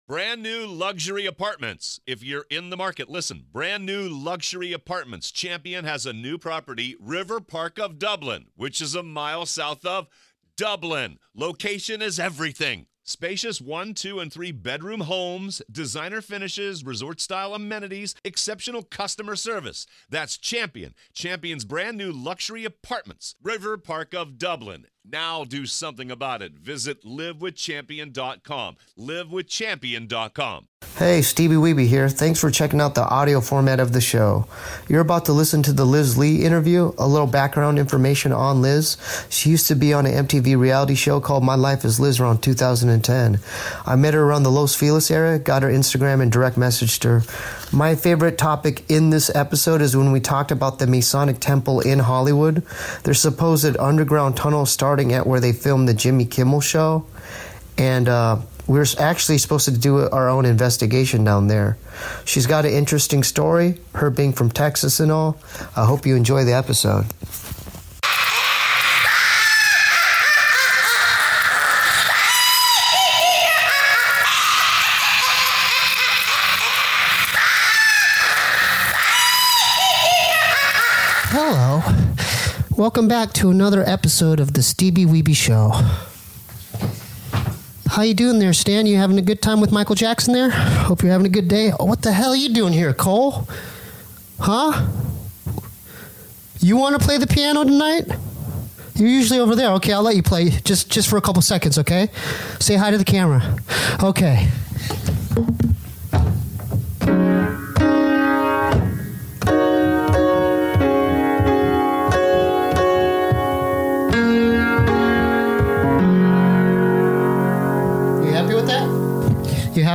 We talk blood sacrifices, reality TV, fan mail, poo monsters, and even sing a few praise songs.